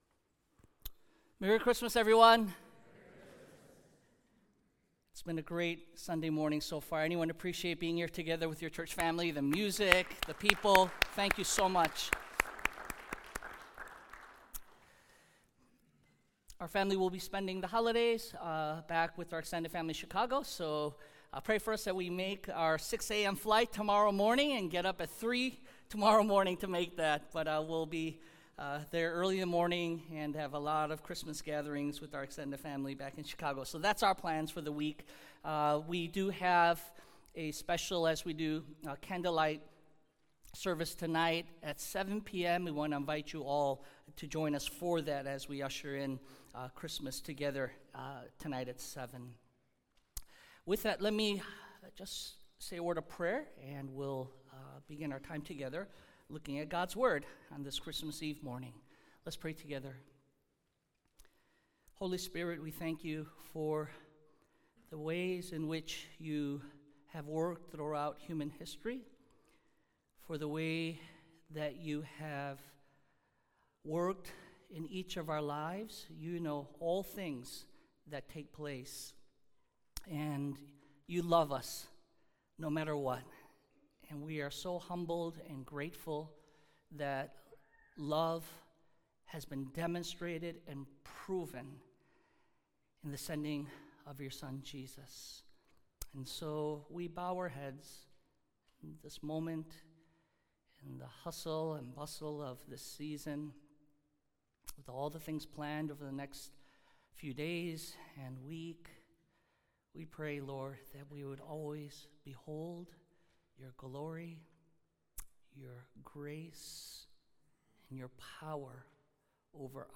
"Christmas in Nazareth" - Morning Service